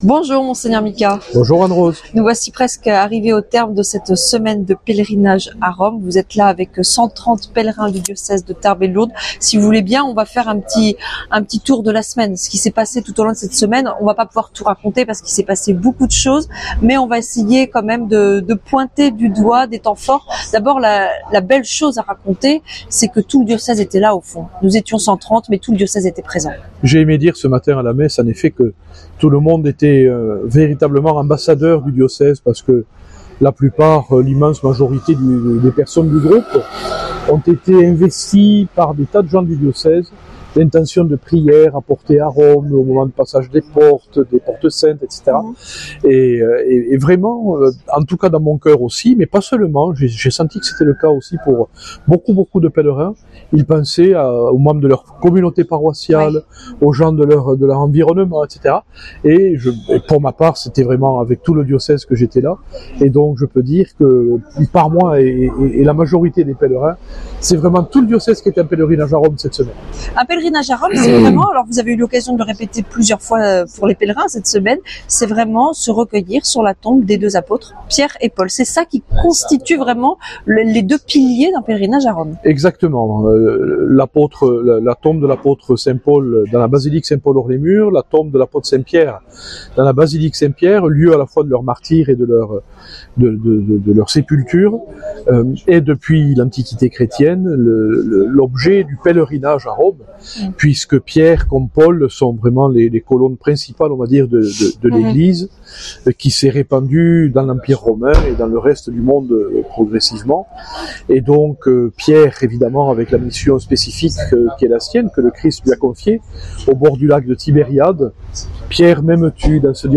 Entretien avec Mgr Micas - Évêque de Tarbes Lourdes